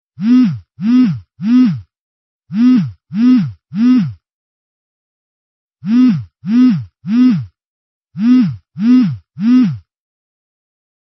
Mobile Vibration-sound-HIingtone
mobile-vibration_25070.mp3